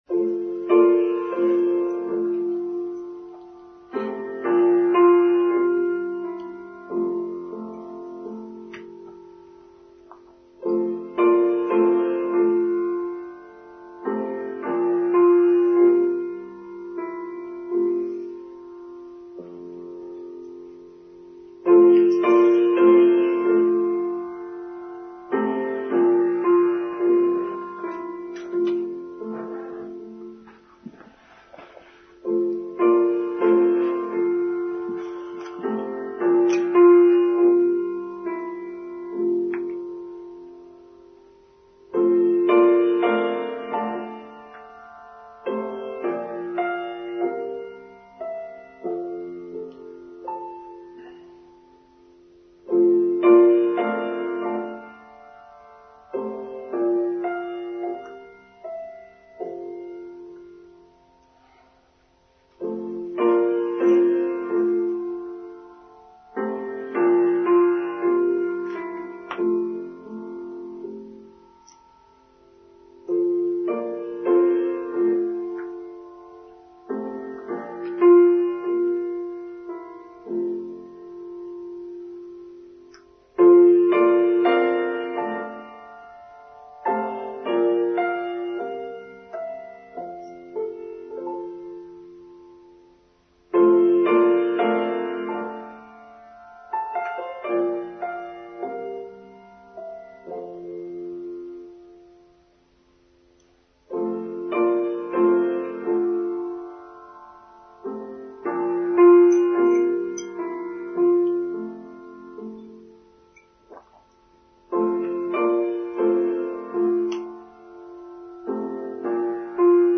Gratitude and Generosity: online service for Sunday 4th December 2022